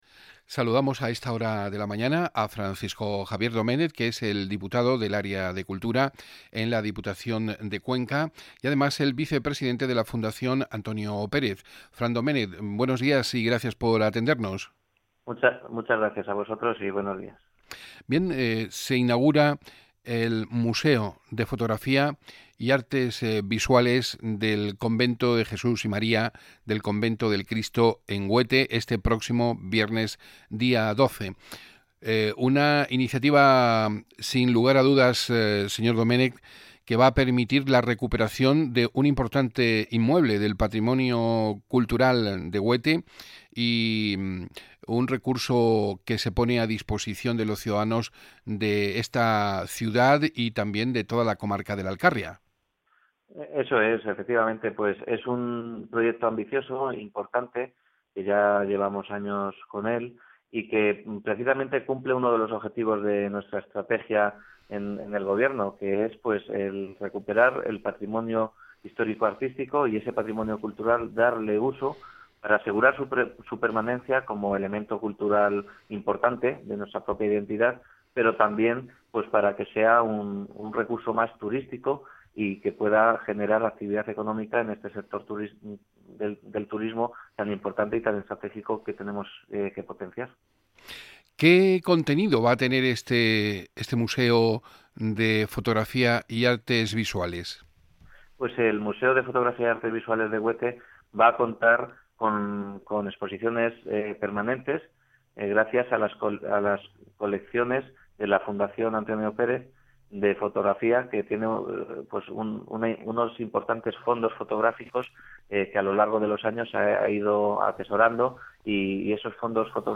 Entrevista en RNE sobre el Museo de Fotografía